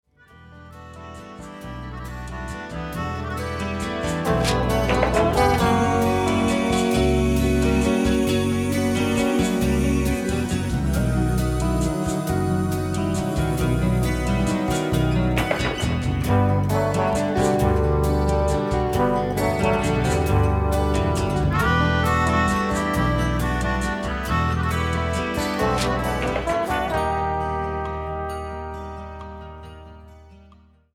- Recorded and mixed at AVAF Studios, Zurich, Switzerland